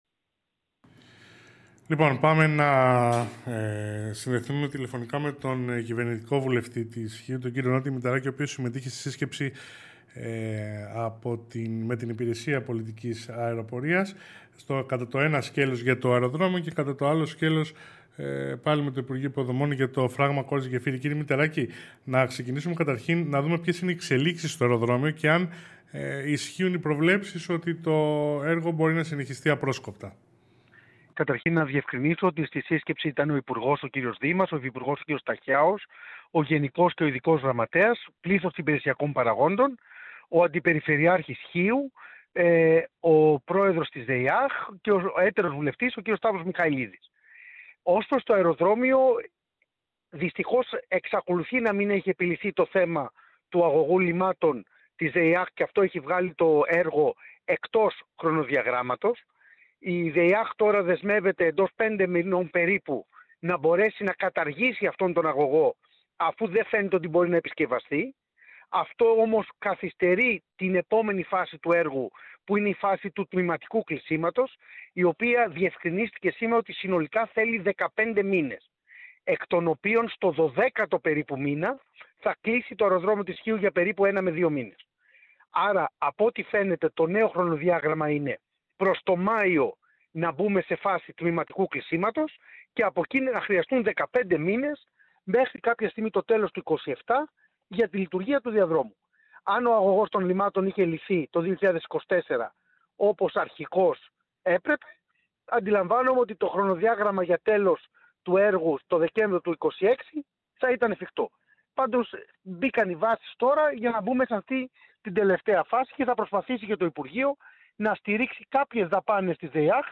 Μηταράκης τηλεφωνική για αεροδρόμιο
Μηταράκης τηλεφωνική για αεροδρόμιο.mp3